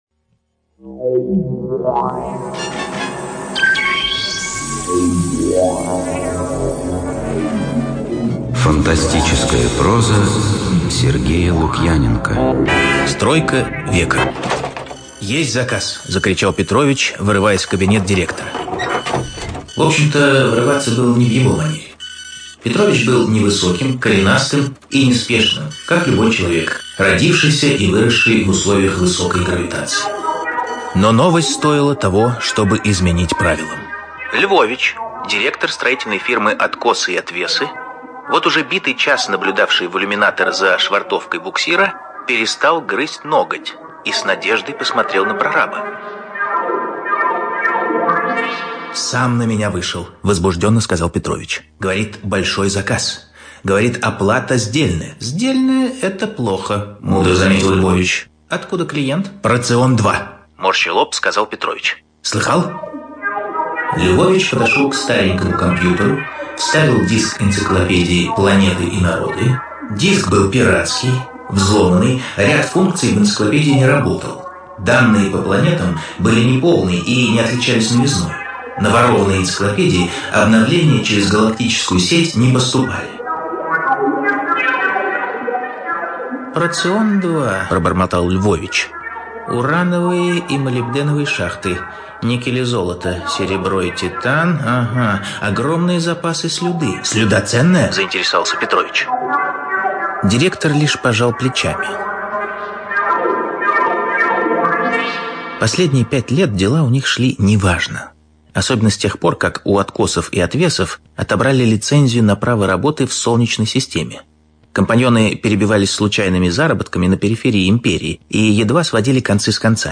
ЖанрФантастика, Юмор и сатира